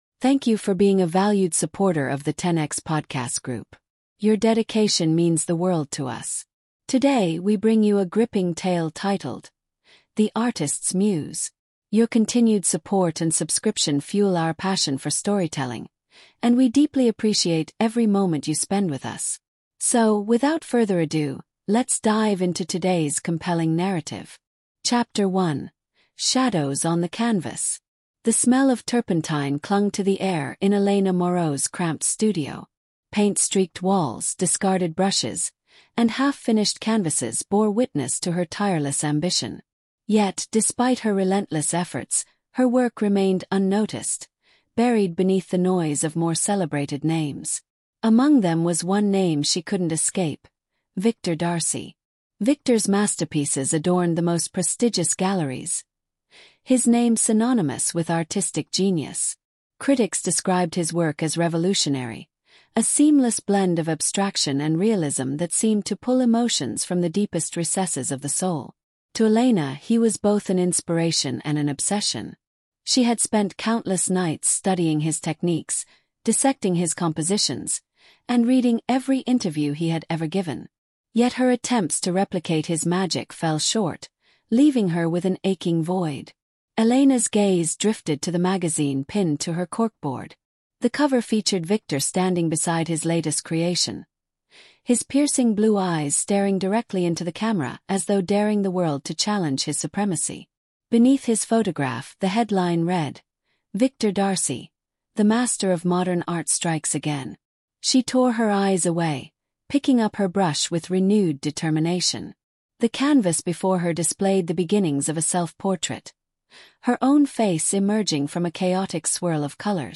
The Artist’s Muse is a gripping storytelling podcast that explores the intoxicating world of art, ambition, and manipulation. Follow Elena Moreau, a talented but struggling painter, as she enters the life of renowned artist Victor D’Arcy to unlock his secrets and rise in the art world. What begins as a quest for greatness transforms into a psychological battle of wits and wills, where truth, betrayal, and identity collide.